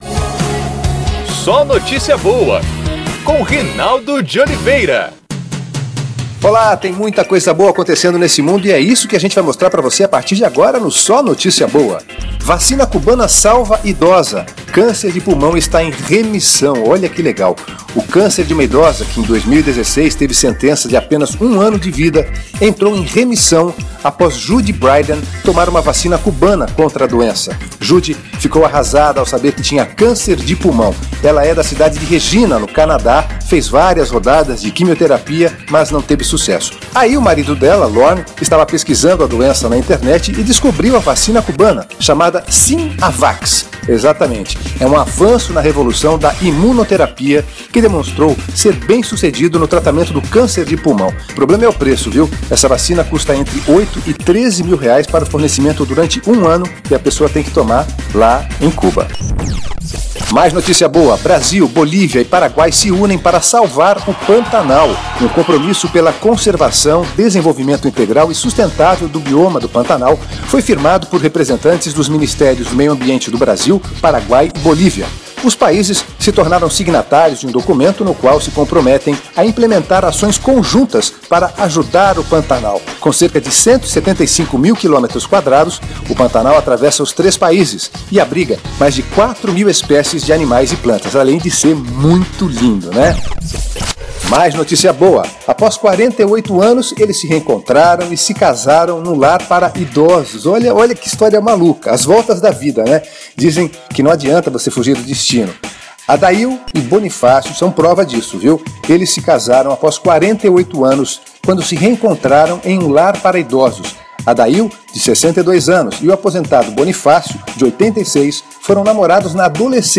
Quer ouvir notícia boa, em vez de ler? Então ouça o programa de rádio do SóNotíciaBoa, o nosso podcast!